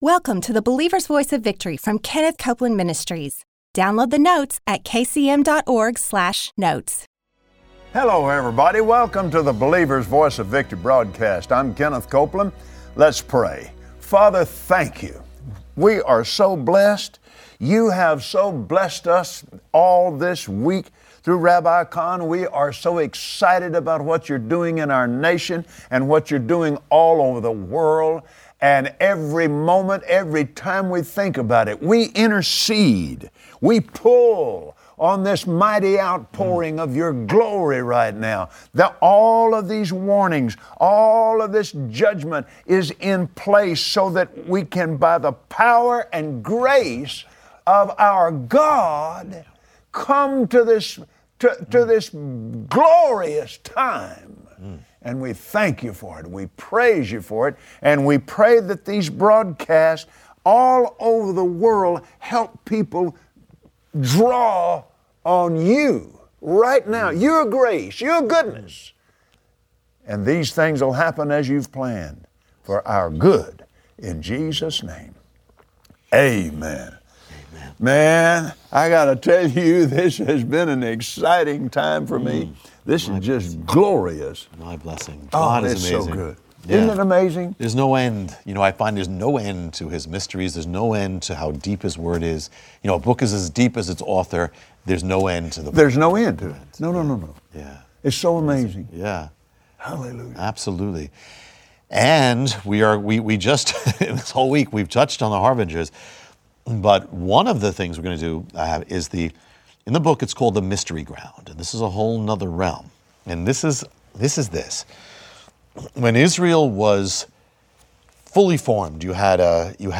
Today Kenneth Copeland and his guest, Rabbi Jonathan Cahn, explain how God is calling America back to its roots. Join them as they uncover this nation’s foundation of faith.